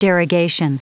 Prononciation du mot derogation en anglais (fichier audio)
Prononciation du mot : derogation